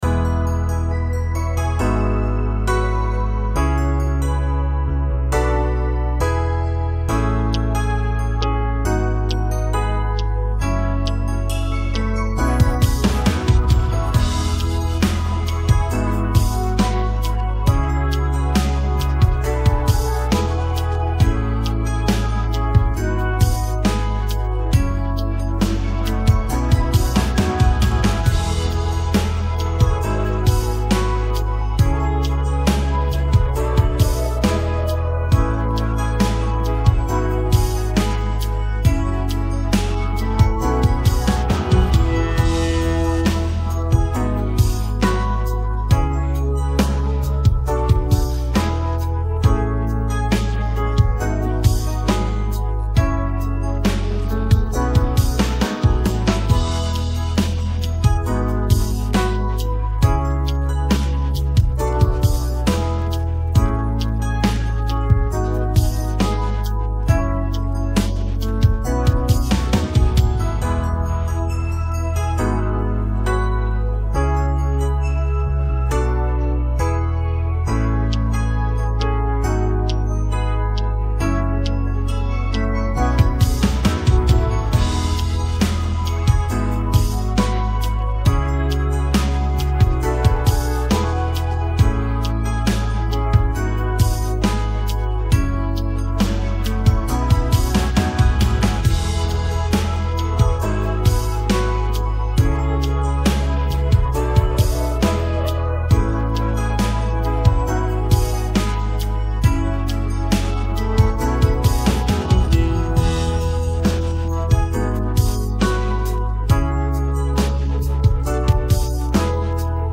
เพลงร้ย